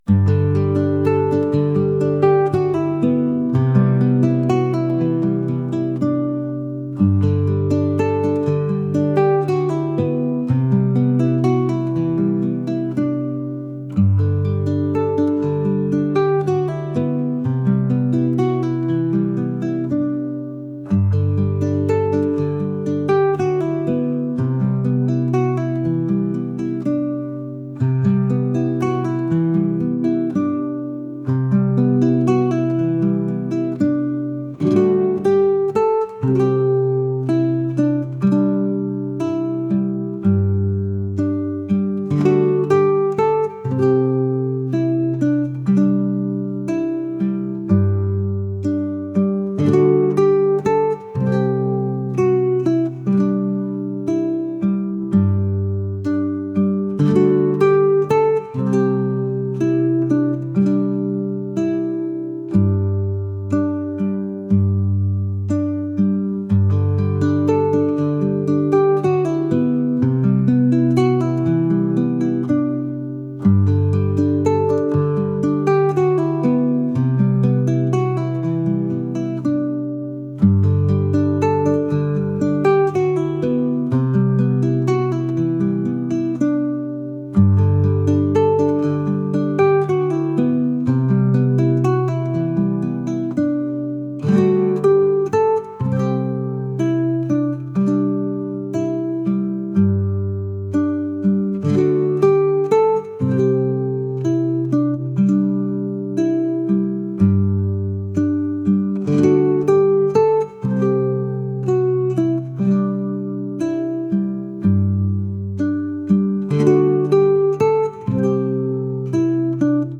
indie | folk | ambient